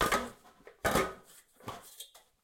Aurora.3/sound/effects/ladder2.ogg
ladder2.ogg